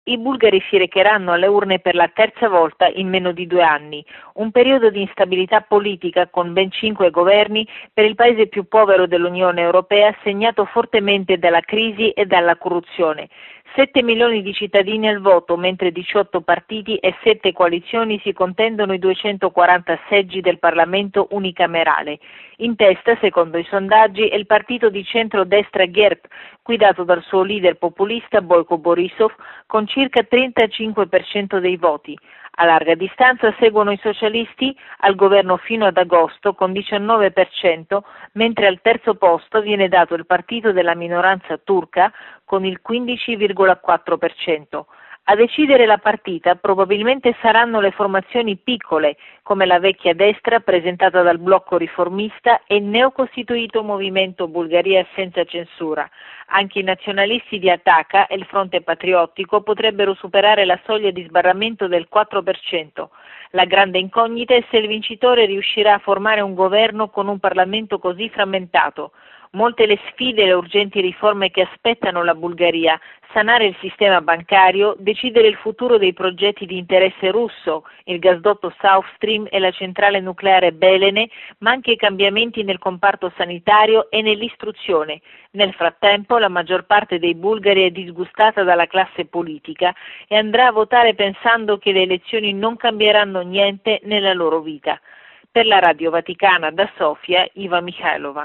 Bollettino Radiogiornale del 04/10/2014